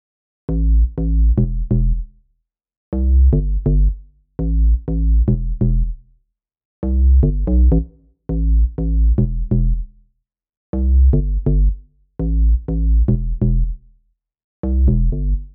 Bass Loop
The bassline in this track is a simple FM Bass.
The sound is almost there.